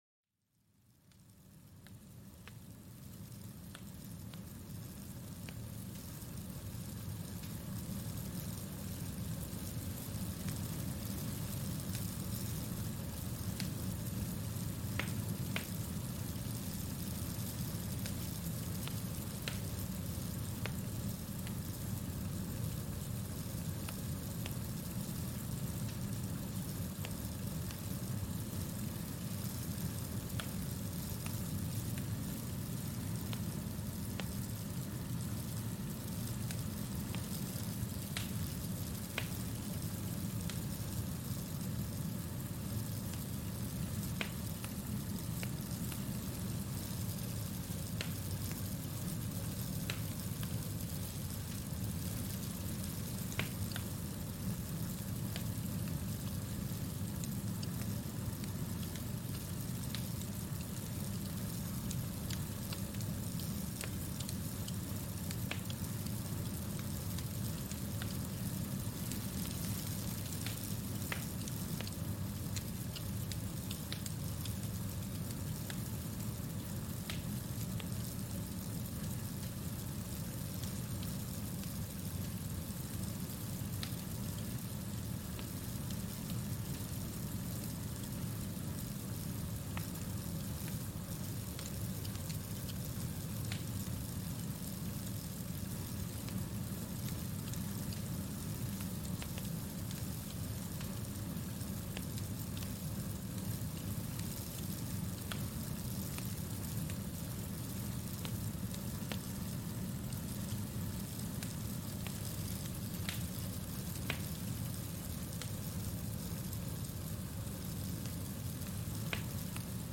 Crepitar de la Chimenea: Relajación y Serenidad